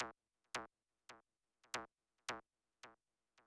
Index of /90_sSampleCDs/Best Service ProSamples vol.54 - Techno 138 BPM [AKAI] 1CD/Partition C/UK PROGRESSI
FLANGE HIT-R.wav